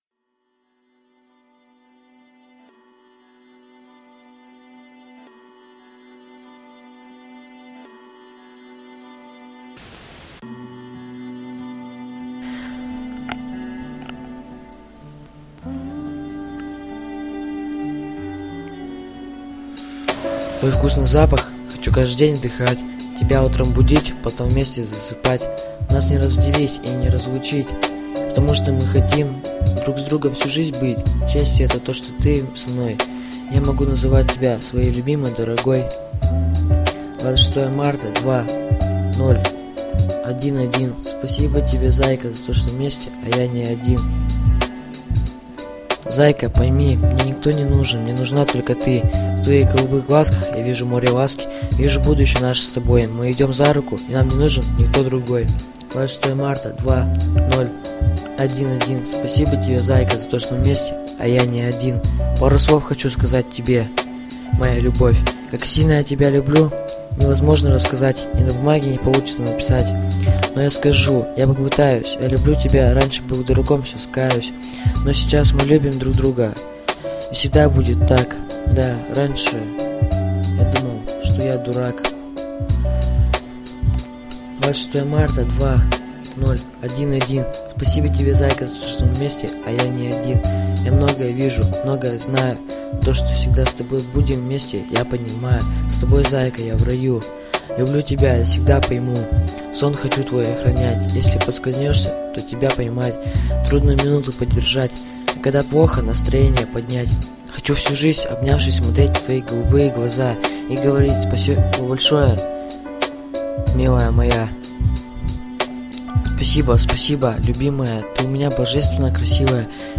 Любовный рэп